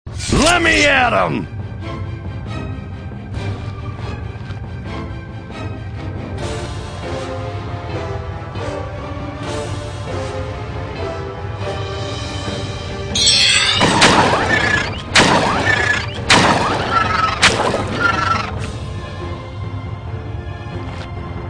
• Feuert in jeder Runde mehrere Salven mit jeweils (!) bis zu 4 Schüssen ab!
OmnigunMk12.mp3